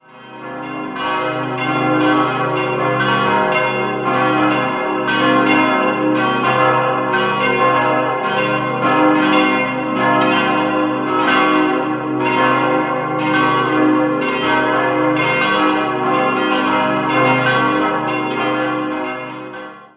5-stimmiges erweitertes C-Moll-Geläute: c'-es'-g'-b'-c'' Alle Glocken wurden von der Gießerei Wolfart in Lauingen gegossen.